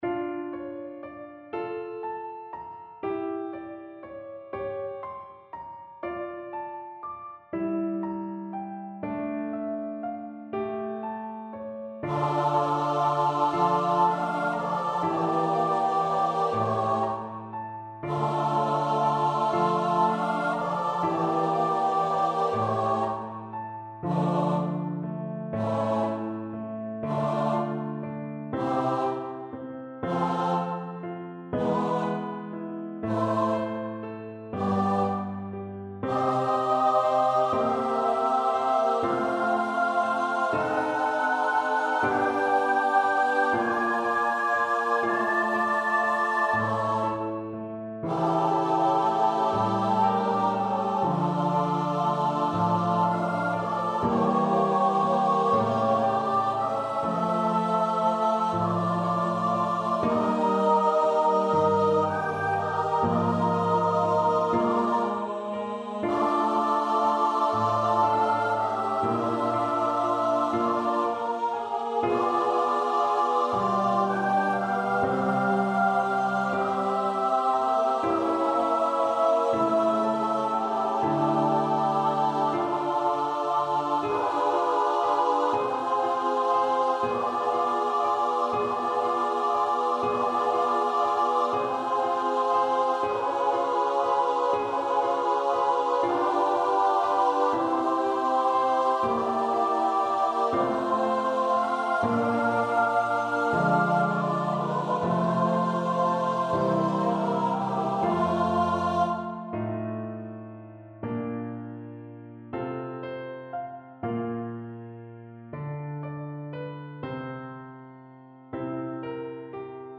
Lacrimosa (Requiem) Choir version
Free Sheet music for Choir
Choir  (View more Intermediate Choir Music)
Classical (View more Classical Choir Music)